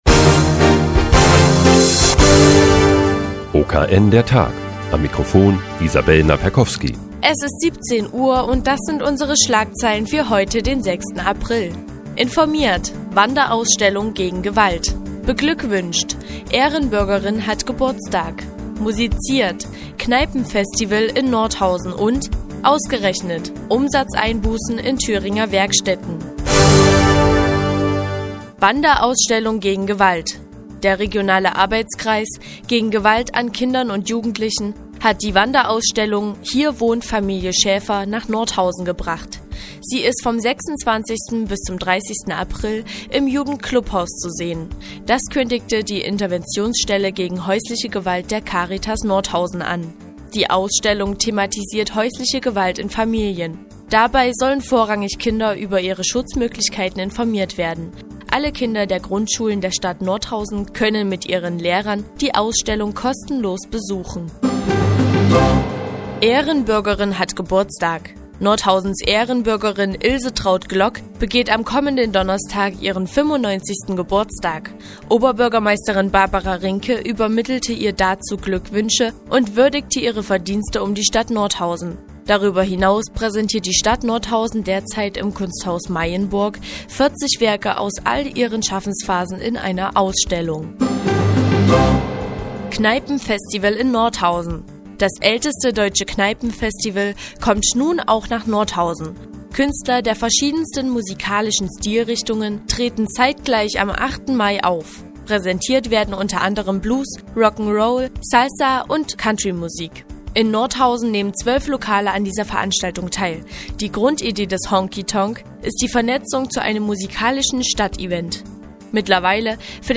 Die tägliche Nachrichtensendung des OKN ist nun auch in der nnz zu hören. Heute geht es um eine Wanderausstellung gegen Gewalt und ein Kneipenfestival in Nordhausen.